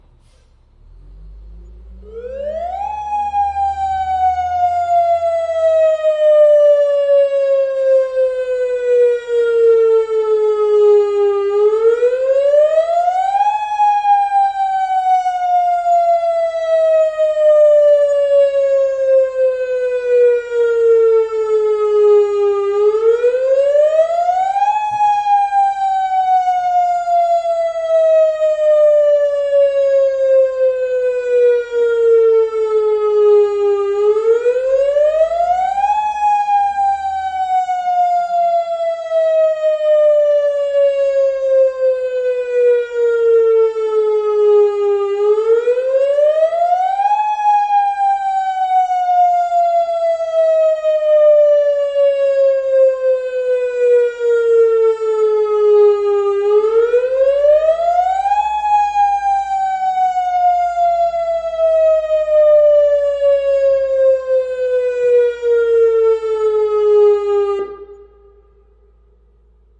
Звук сирены, предупреждающей о приближающемся цунами